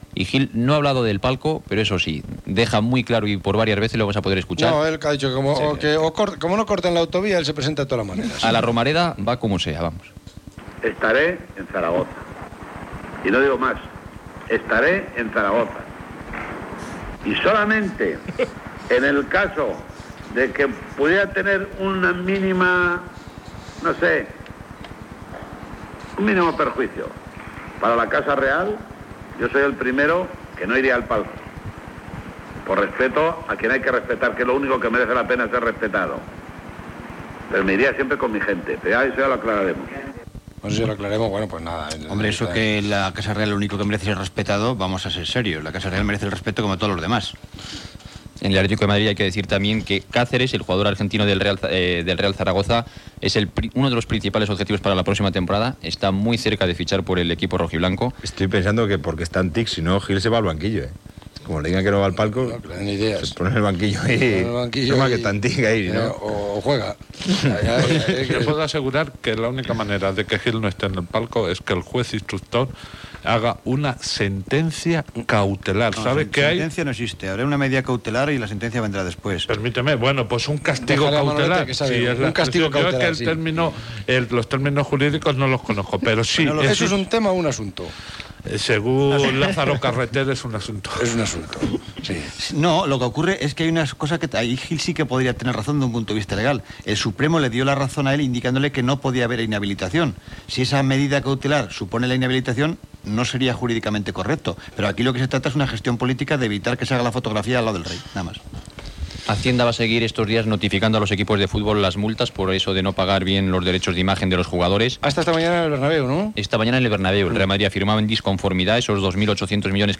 Jesús Gil anirà a la Romareda, noticies de futbol internacional, París Niça de ciclisme, etc. Gènere radiofònic Esportiu